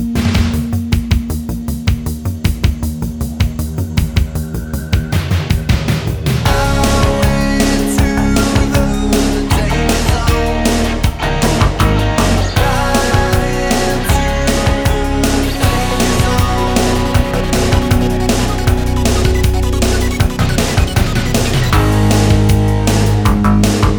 no Backing Vocals Soundtracks 3:32 Buy £1.50